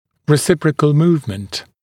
[rɪ’sɪprəkl ‘muːvmənt][ри’сипрэкл ‘му:вмэнт]взаимное перемещение